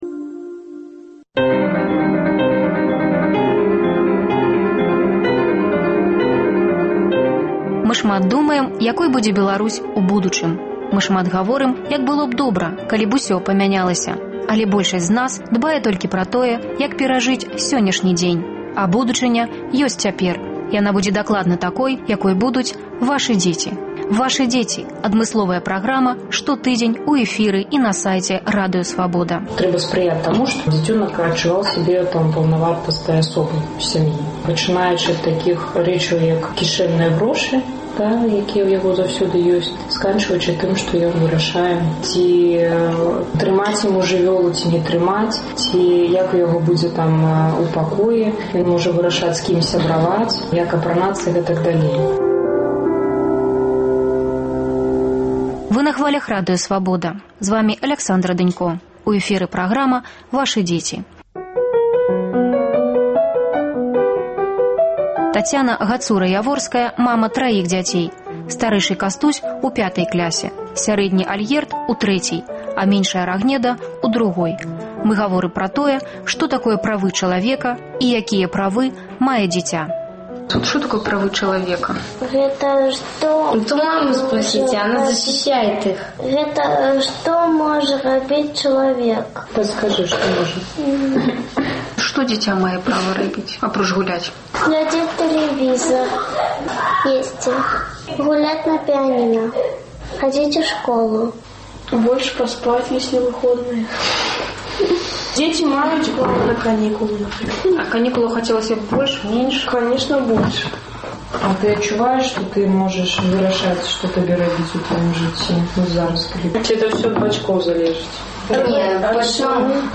Ці можа дзіця выбіраць ці ісьці яму на акцыю разам з бацькамі? Дарослыя і дзеці адказваюць на гэтыя пытаньні ў новым выпуску перадачы "Вашы дзеці".